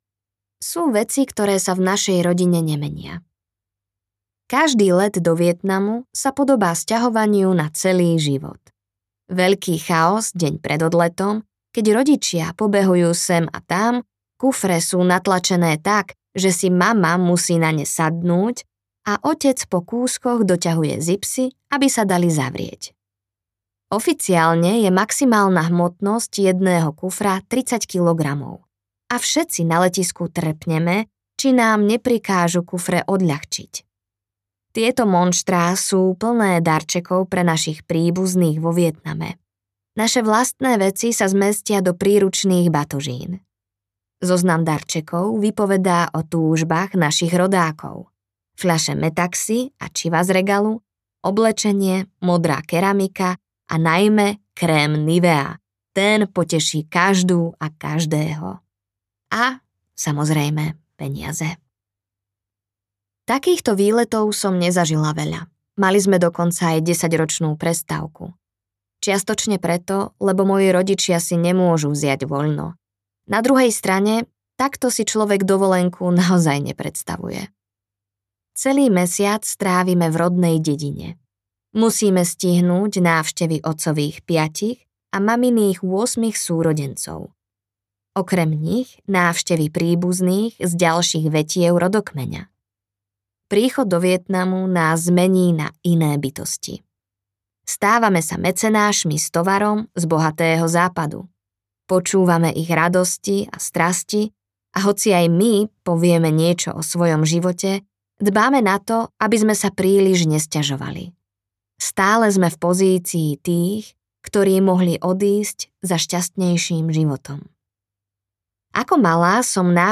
Audio knihaVšetko, čo nás spája
Ukázka z knihy